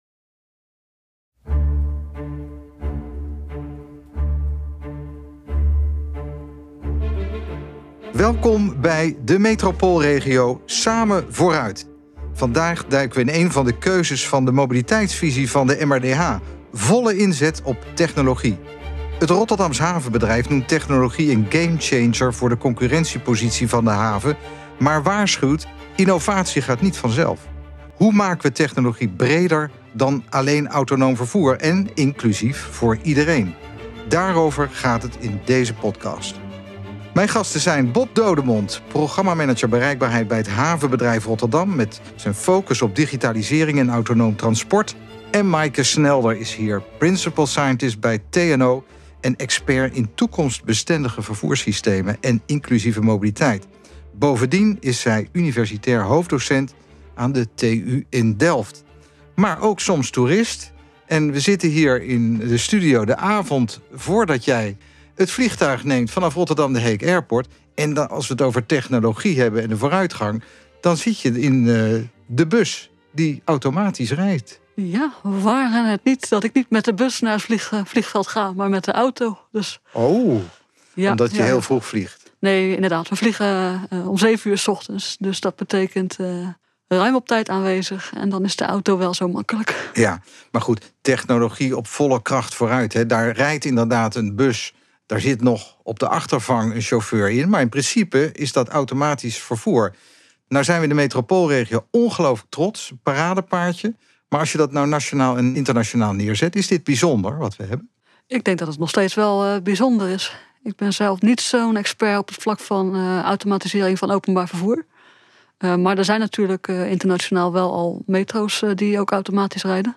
twee experts op het gebied van bereikbaarheid en economie